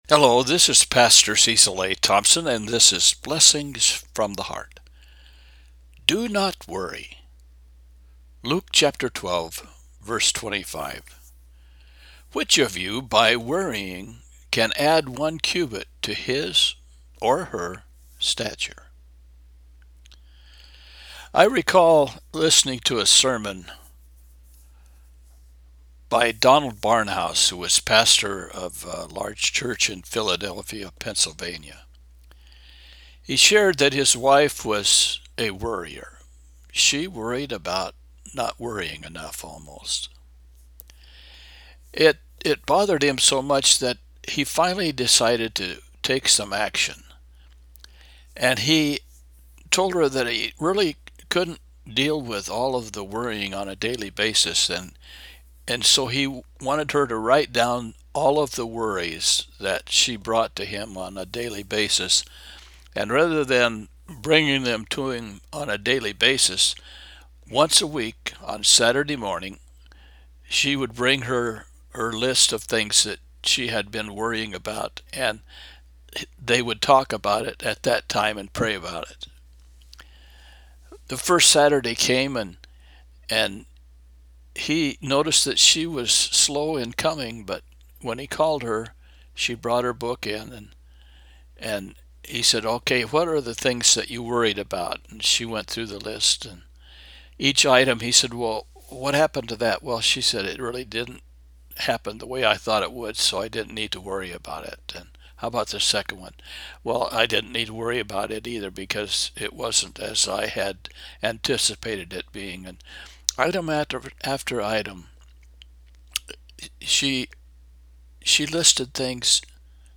Luke 12:25 – Devotional